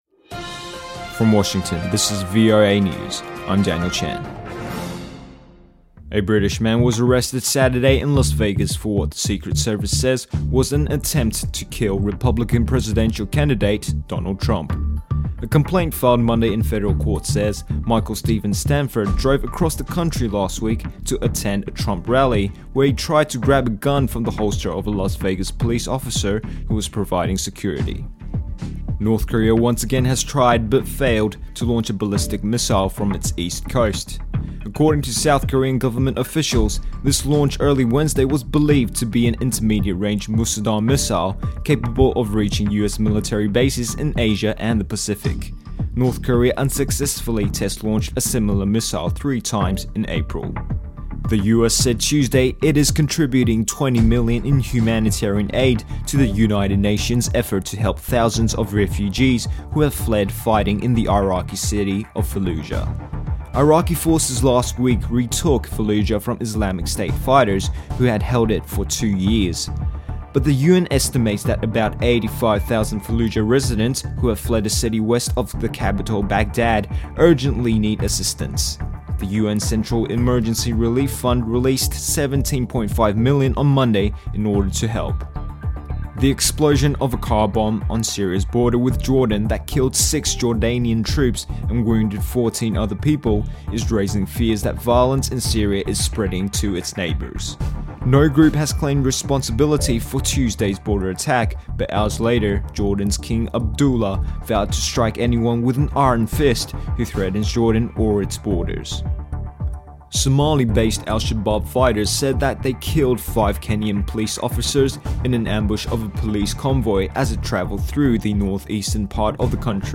English News reading practise 22 June 2016.
Australian Broadcasting Corporation (ABC) owns all rights to background music.